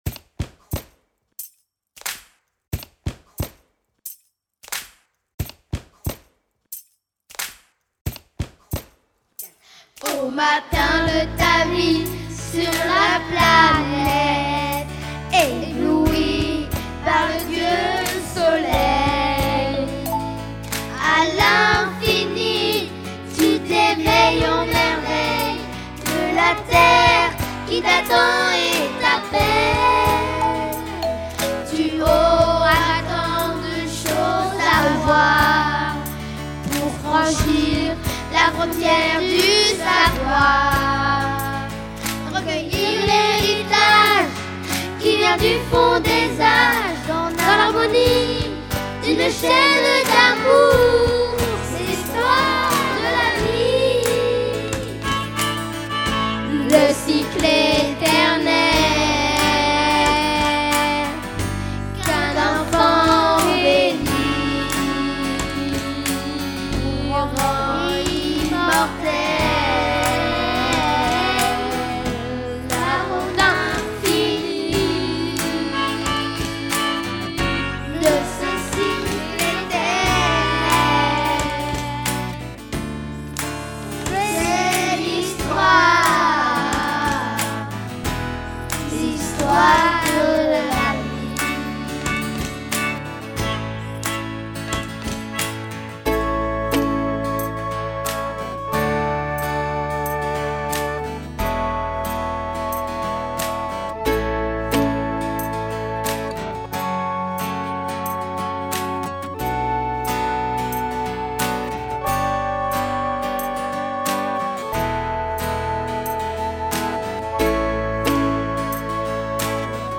les enfants ont enregistré une reprise